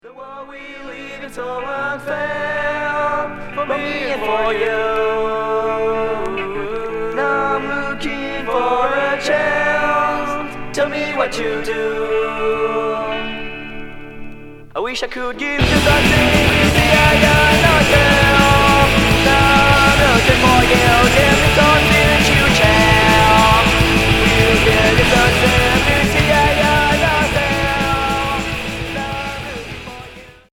Skatecore